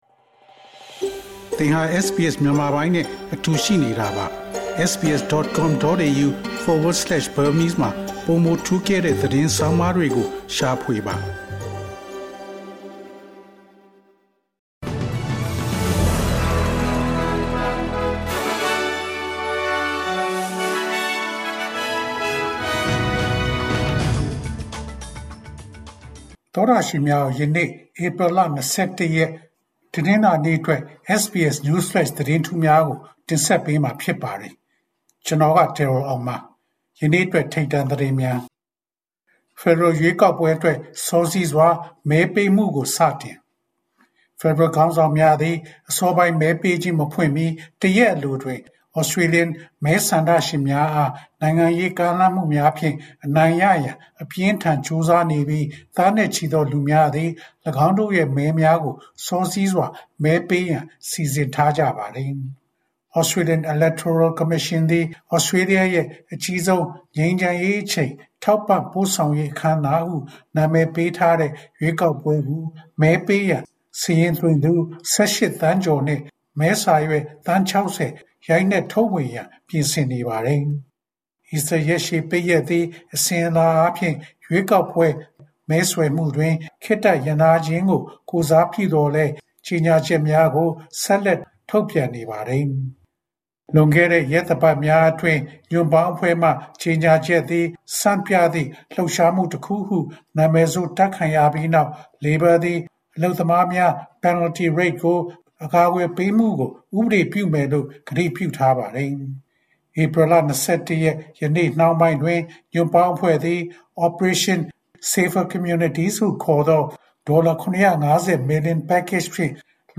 SBS မြန်မာ ၂၀၂၅ ခုနှစ် ဧပြီ ၂၁ ရက် နေ့အတွက် News Flash သတင်းများ။